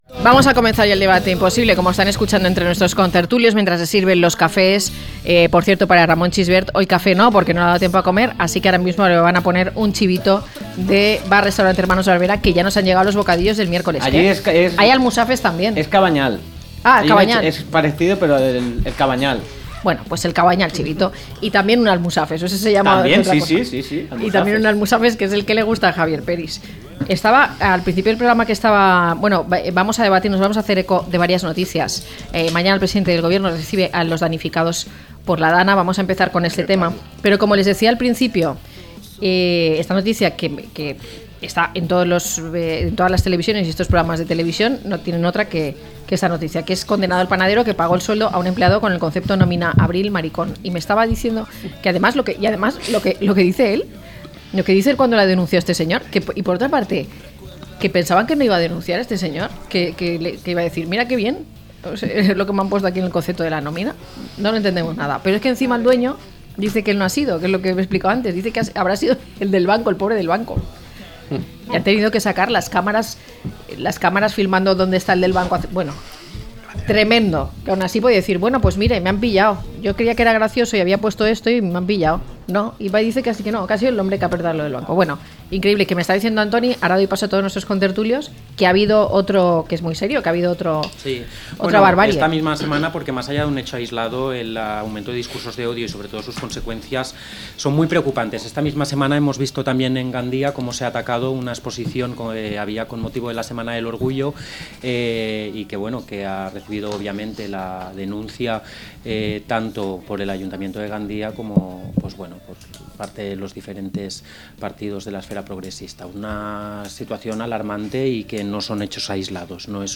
La visita de Pedro Sánchez a Valencia y los estudios universitarios, a debate - La tarde con Marina
0521-LTCM-DEBATE.mp3